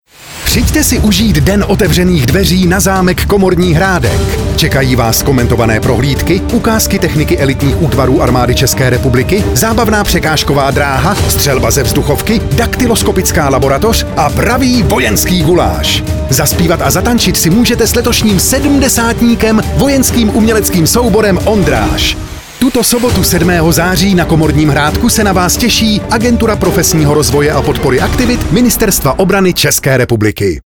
dod-komorni-hradek---audiopozvanka.mp3